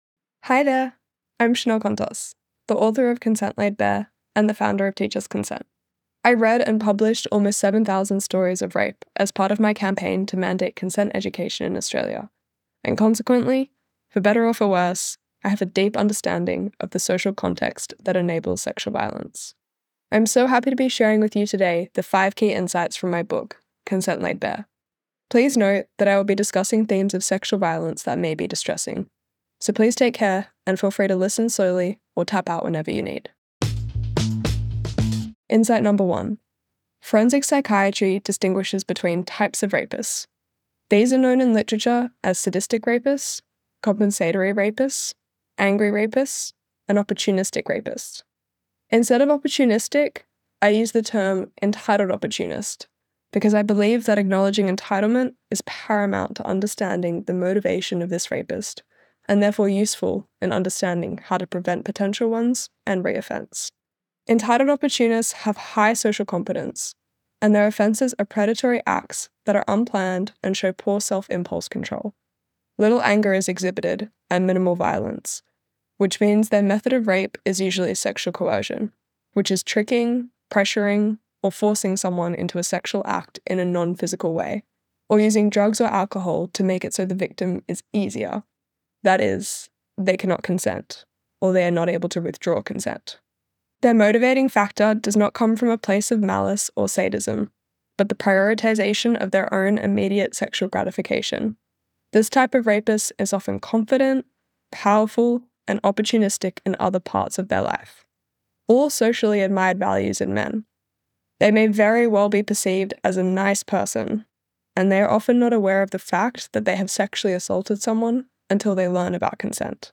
Below, Chanel shares five key insights from her new book, Consent Laid Bare: Sex, Entitlement, and the Distortion of Desire. Listen to the audio version—read by Chanel herself—below, or in the Next Big Idea App.